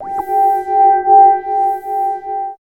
71 SCI-FI -L.wav